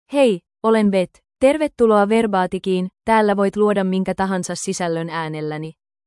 FemaleFinnish (Finland)
Voice sample
Female